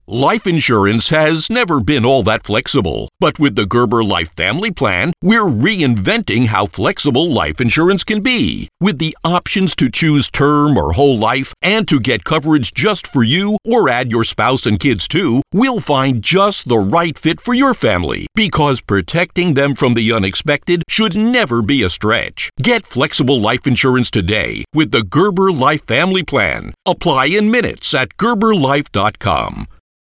Comercial, Profundo, Natural, Llamativo, Amable
Comercial